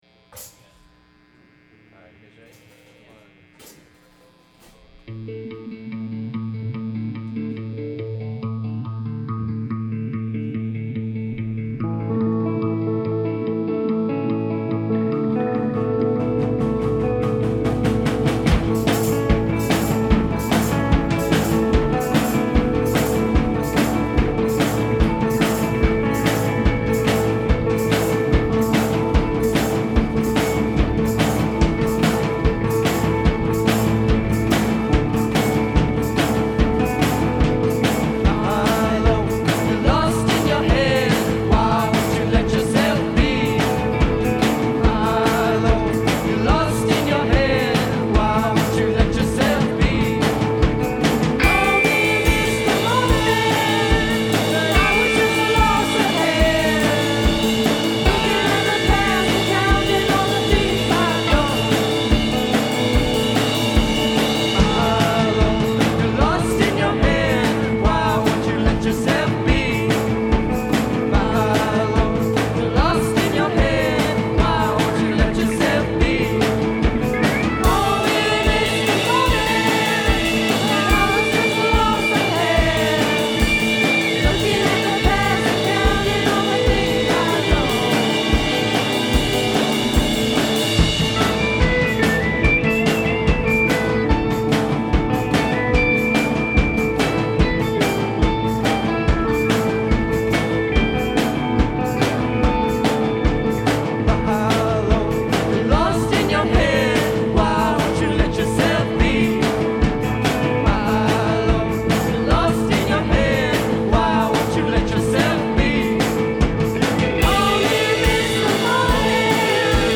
neo-primitive hippie folk-rock